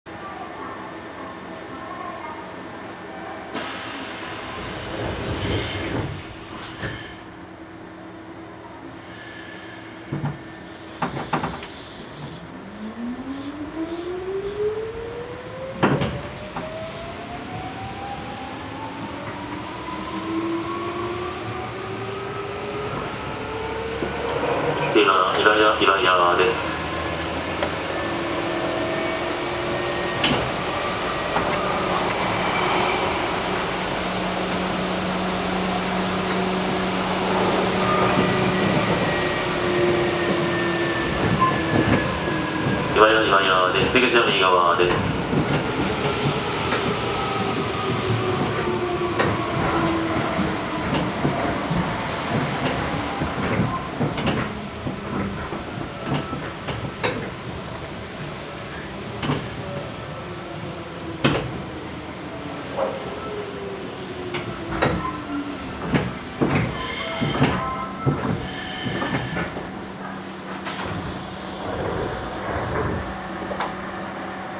走行機器は電動カム軸式の抵抗制御で、定格90kWのTDK-8145-A形モーターを制御します。
走行音
録音区間：西灘～岩屋(お持ち帰り)